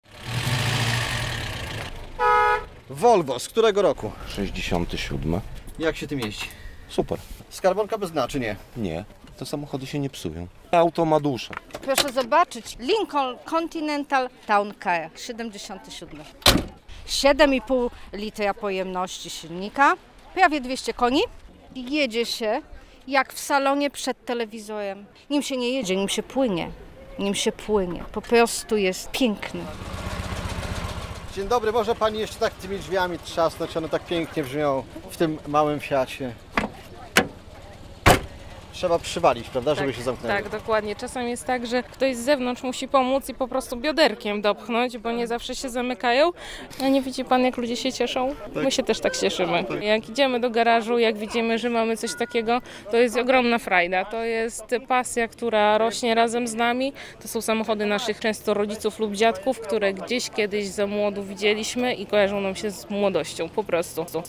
Zlot samochodów zabytkowych w Supraślu - relacja